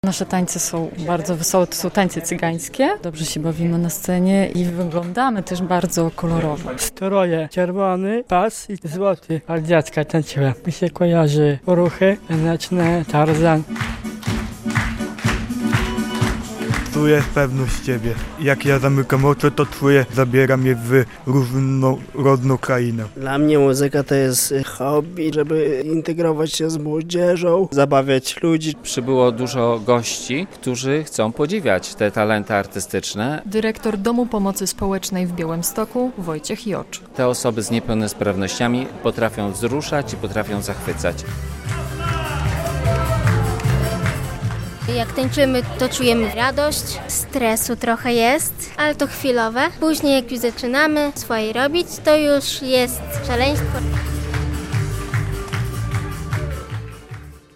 Osoby z niepełnosprawnością zaprezentowały swój taniec, wokal oraz talent aktorski. W poniedziałek (6.10) w Białostockim Teatrze Lalek odbył się XXVIII Przegląd Twórczości Artystycznej Osób Niepełnosprawnych - wydarzenie, które od lat gromadzi artystów z różnych części Polski, dając im przestrzeń do prezentacji talentów i wspólnego działania.